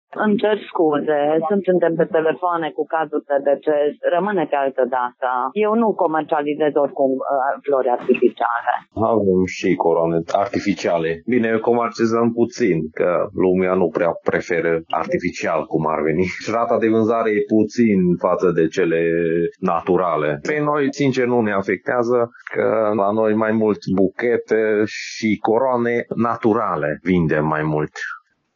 Reprezentanții unor firme de servicii funerare din Tg. Mureș spun că vând puține coroane de plastic, iar în zonă oamenii preferă coroanele și arajamentele florale naturale: